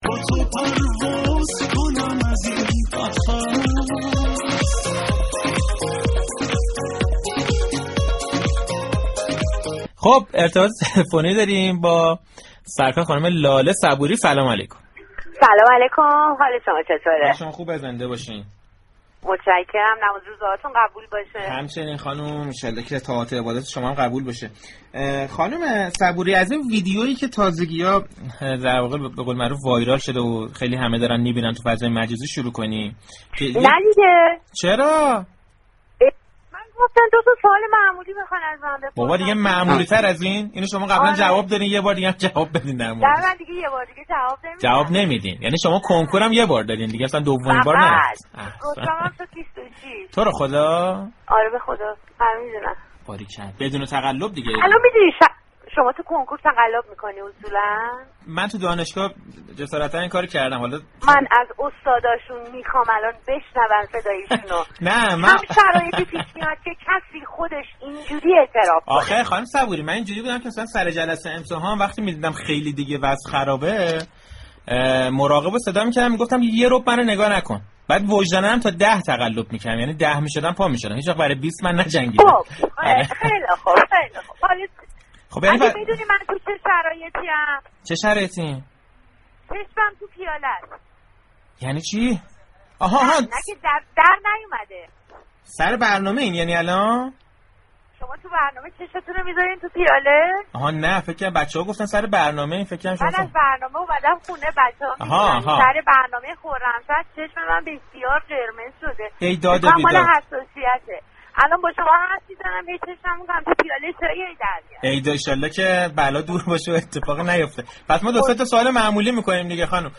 لاله‌ صبوری، بازیگر سینما و تلویزیون كه بیشتر در فیلم‌ها و سریال‌های طنز ایفای نقش كرده است در برنامه‌ی صحنه‌ی رادیو تهران در مورد اجرا در تلویزیون و تجربه‌ها‌ی خود در اجراهای تلویزیونی‌اش برای مخاطبان رادیو تهران صحبت كرد.